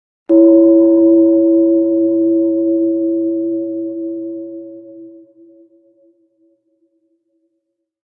Soft-and-soothing-bell-chime-sound-effect.mp3